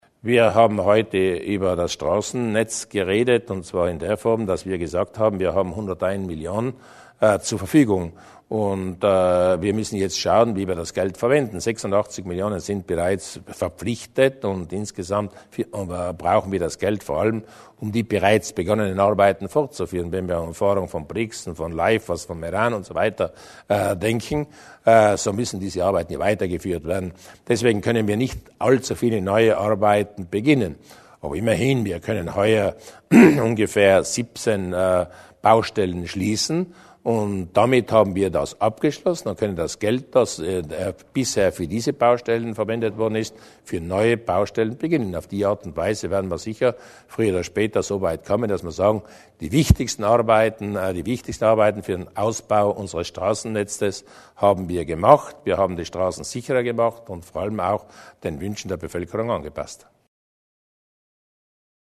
Landeshauptmann Durnwalder zum Bauprogramm des Landes